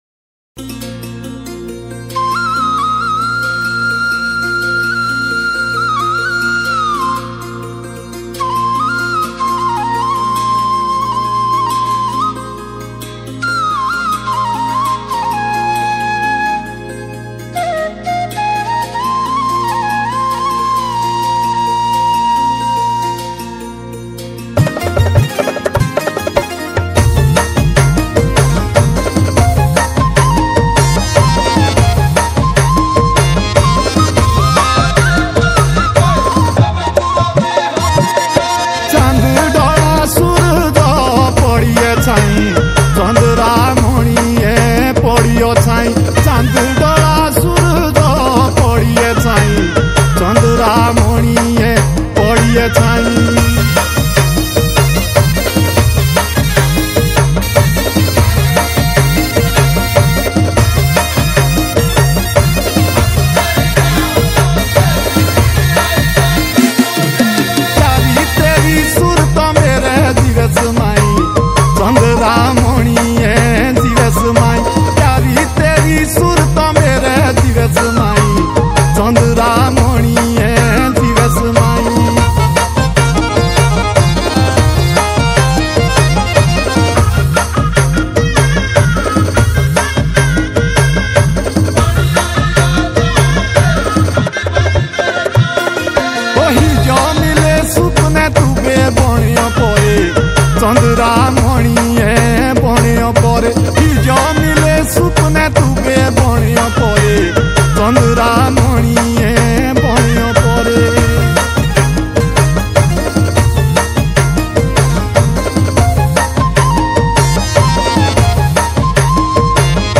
himachali pahari songs